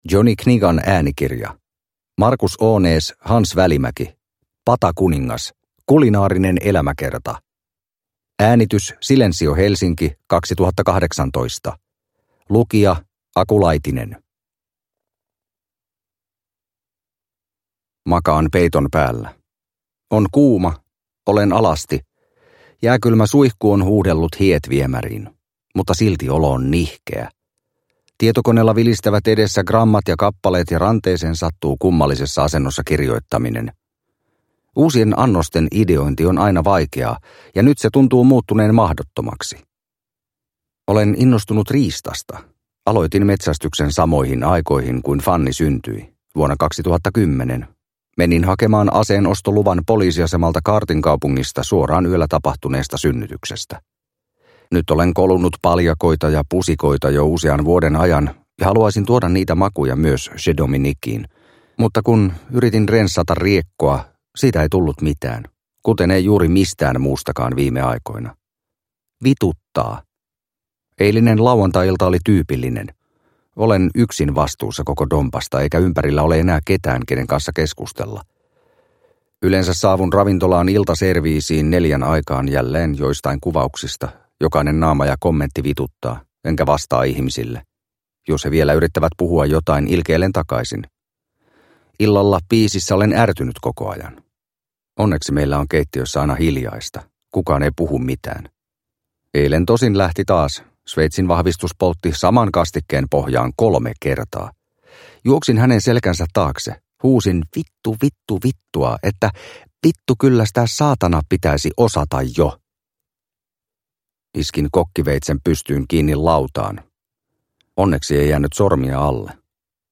Patakuningas (ljudbok) av Markus Ånäs